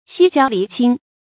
析交离亲 xī jiāo lí qīn
析交离亲发音